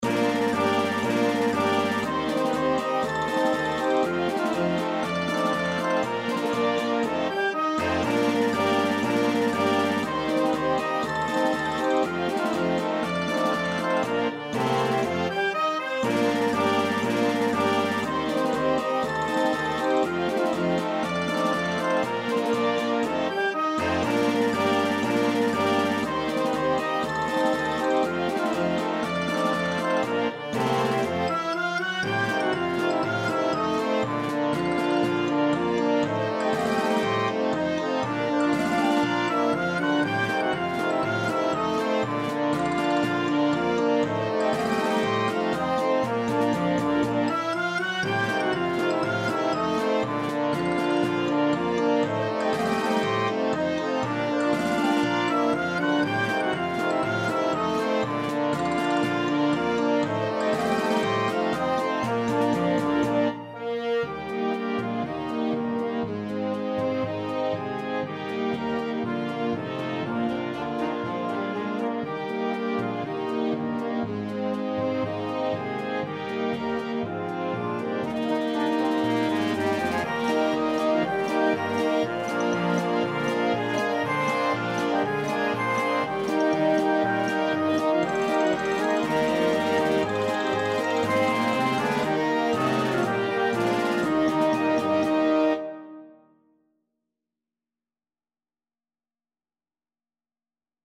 Concert March Level